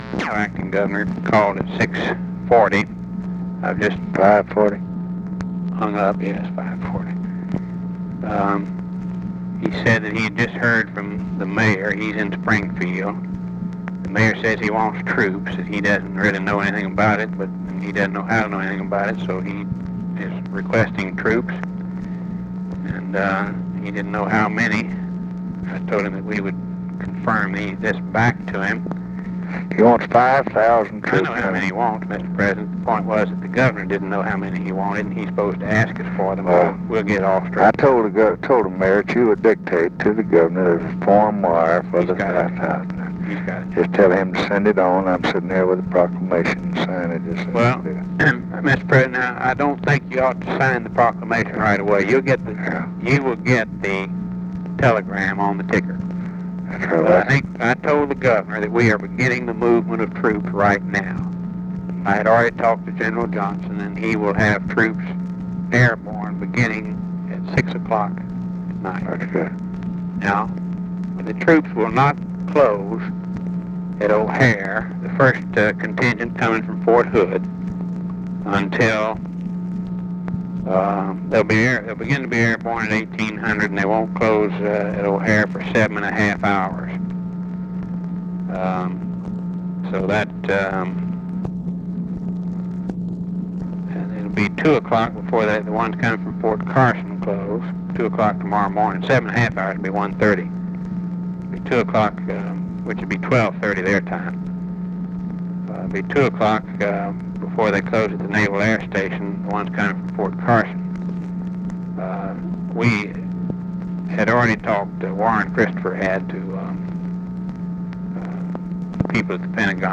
Conversation with RAMSEY CLARK, April 6, 1968
Secret White House Tapes